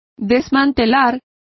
Complete with pronunciation of the translation of dismantle.